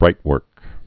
(brītwûrk)